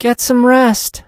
sandy_kill_vo_02.ogg